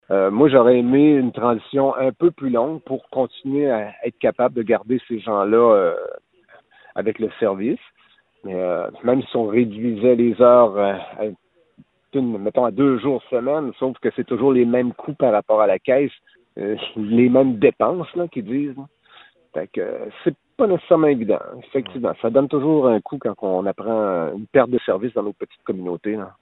Le maire de Sainte-Sophie-de-Lévrard, Jean-Guy Beaudet, comprend les arguments de la Caisse, mais est évidemment déçu :